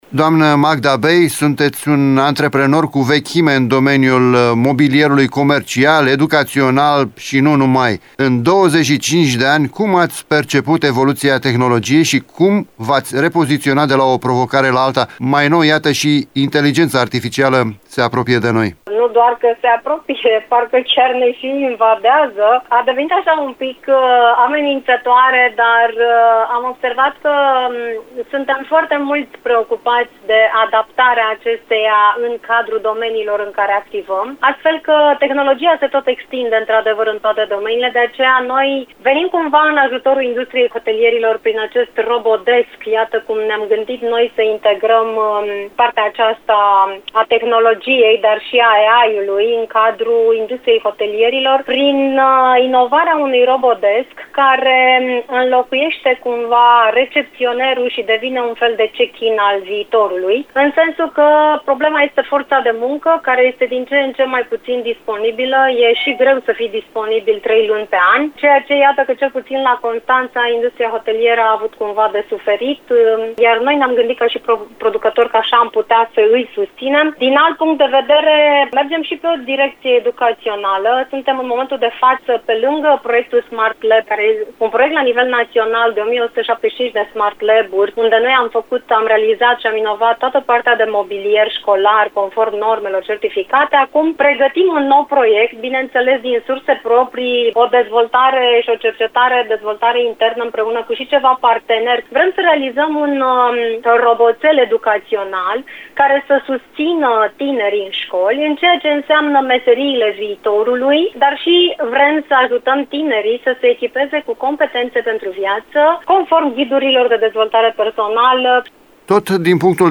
invitată la “Interviul dimineții”